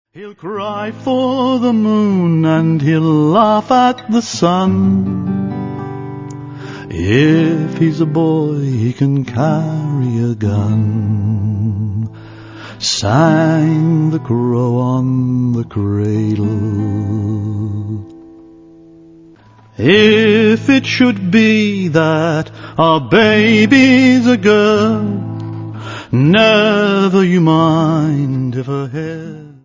Vocals & Guitar
Recorded at Liberty Hall Studios, New Barnet